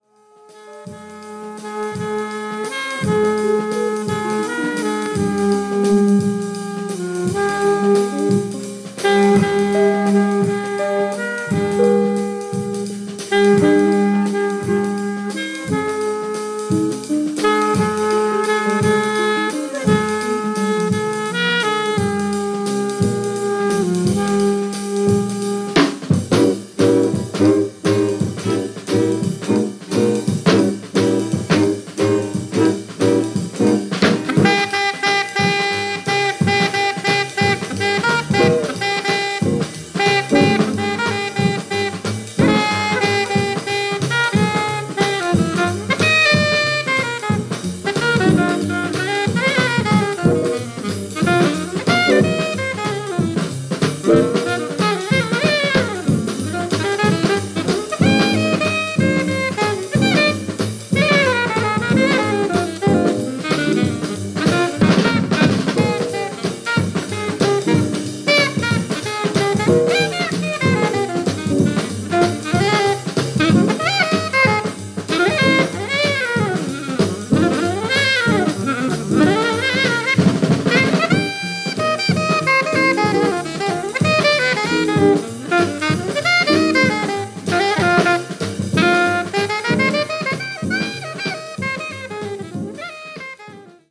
Flute, Alto Saxophone
Bass
Cello
Drums, Percussion